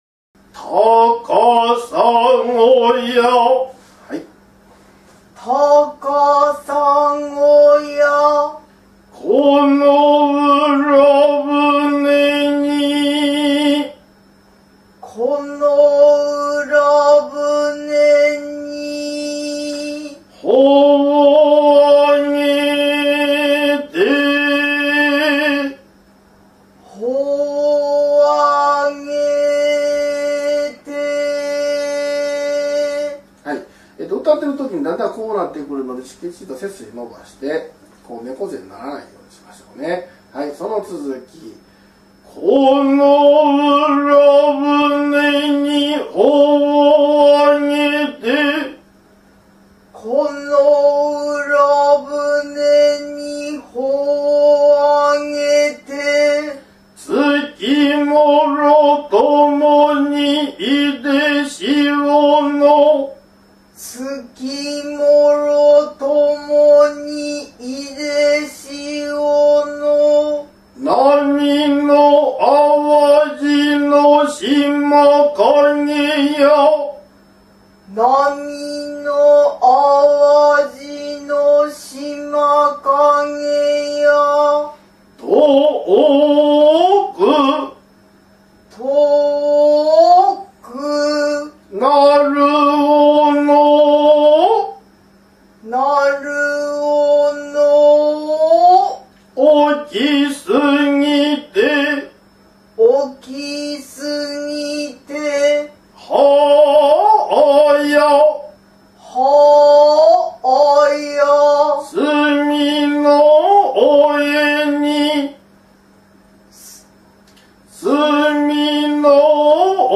★　　能楽 「 高砂 」
「 高砂 」　お稽古